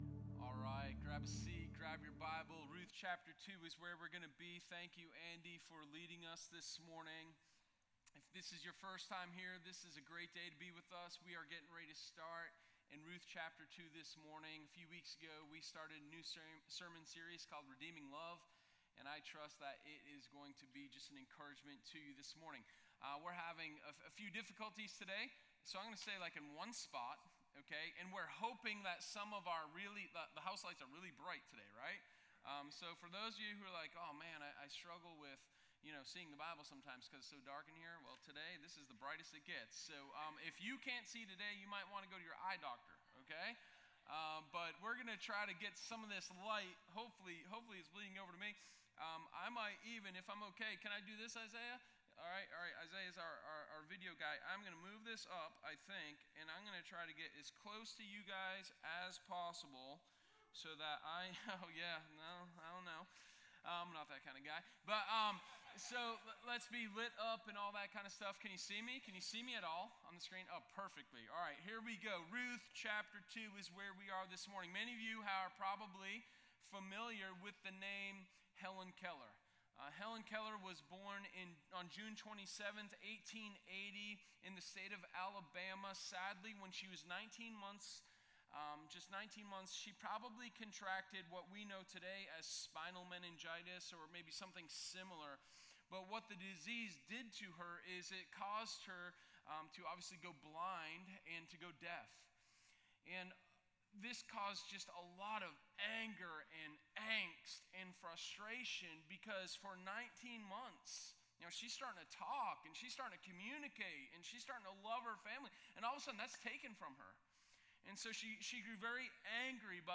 Sermon1025_Confident-in-my-Call.m4a